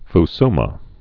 (f-smä)